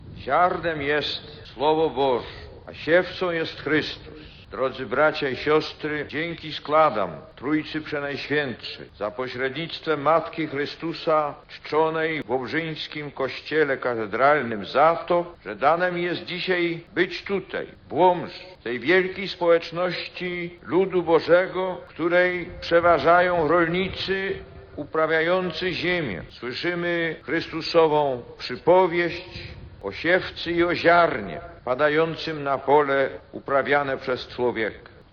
Początek homilii Jana Pawła II w Sanktuarium Miłosierdzia Bożego:
Pierwszego dnia wizyty w stolicy ówczesnego województwa łomżyńskiego Jan Paweł II celebrował uroczystą mszę świętą przed powstającym wówczas Sanktuarium Miłosierdzia Bożego, która zgromadziła około 100 tysięcy wiernych.